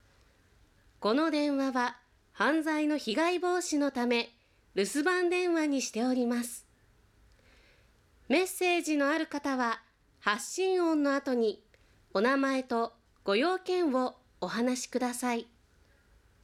メッセージの種類 メッセージの種類は、 ・通常バージョン ・少しだけゆっくり話すバージョン ・低めの声で話すバージョン ・低めの声で少しだけゆっくり話すバージョン の４種類があります。
rusu_narration_hikume_sukosidakeyukkuri.wav